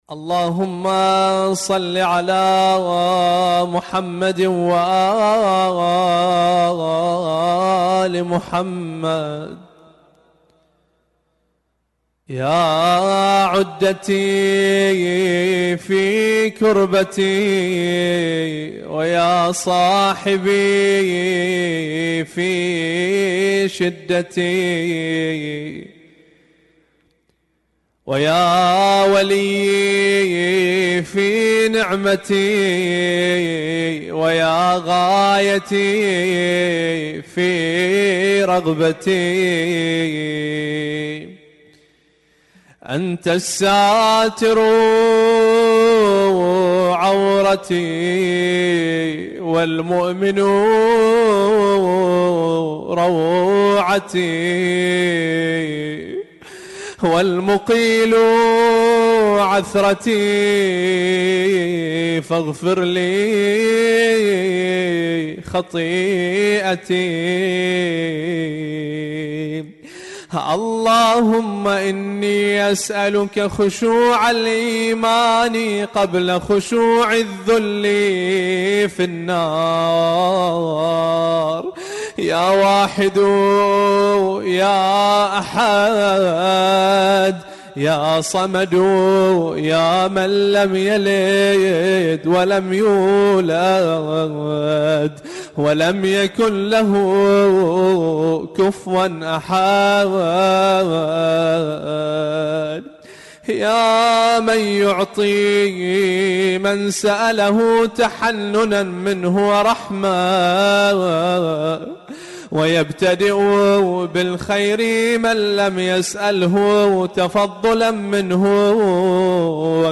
دعاء : یا عدتي